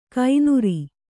♪ kainuri